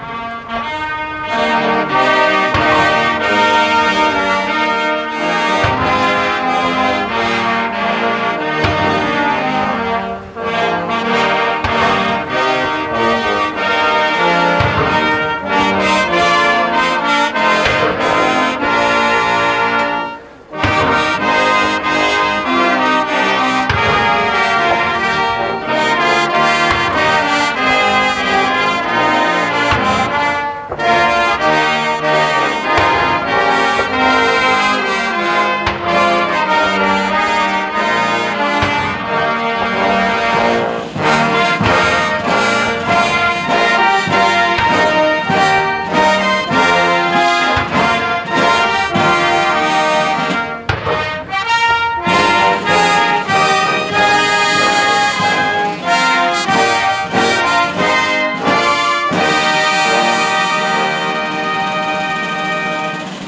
"Die Stem van Suid-Afrika" performed at the White House in 1994.flac